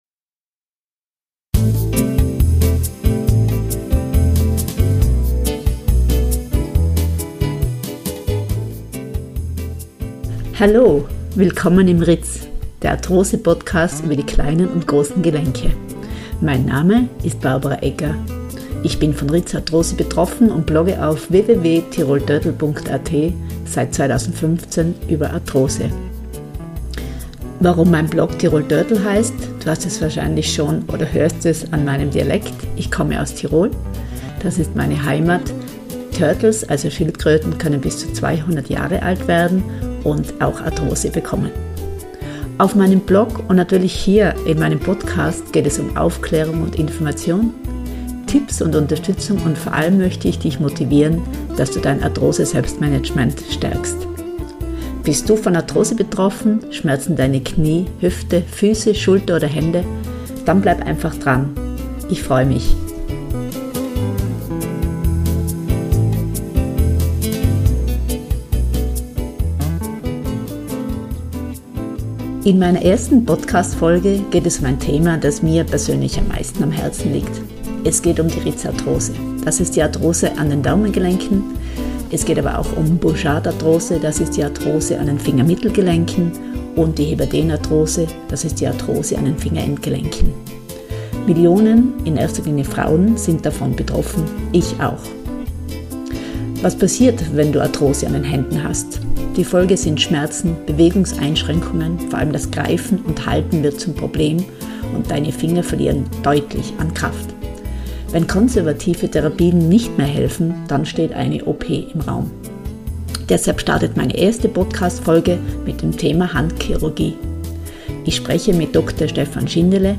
01 Rhizarthrose behandeln I Interview